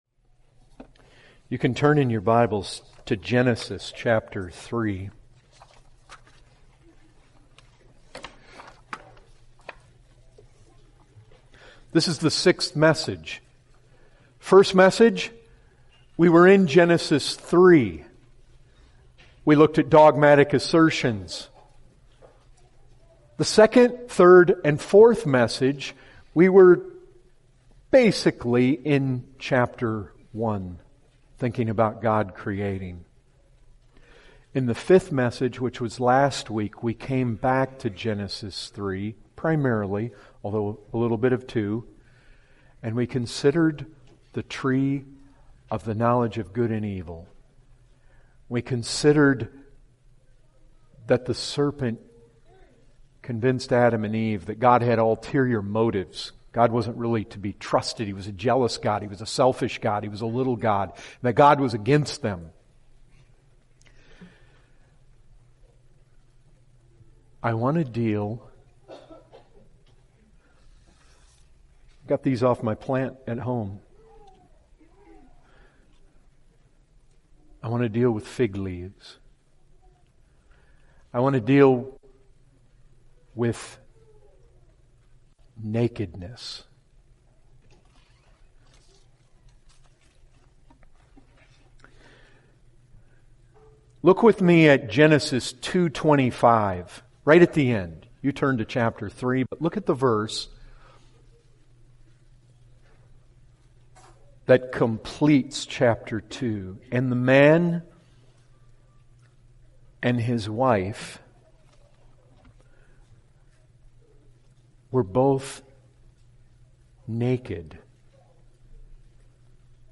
Full Sermons